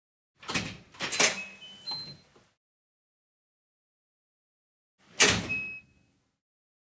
doorSound.wav